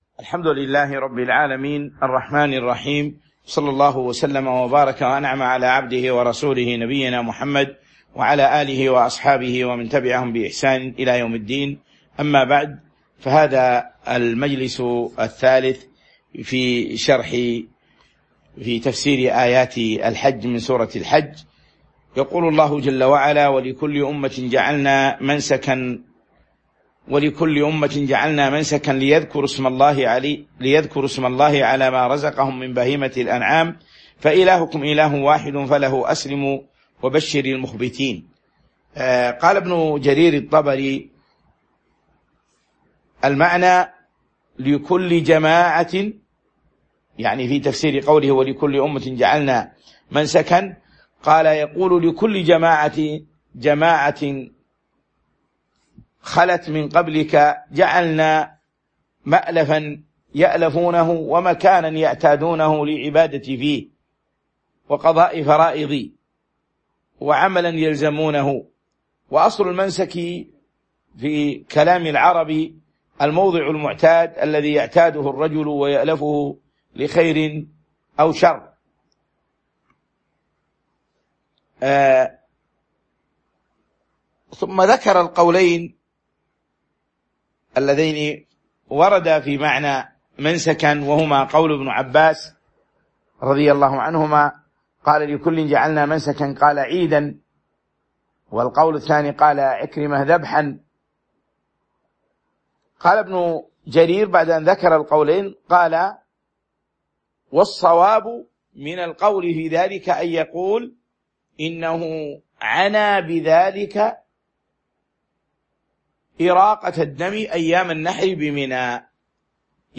تاريخ النشر ٢٣ ذو القعدة ١٤٤٢ هـ المكان: المسجد النبوي الشيخ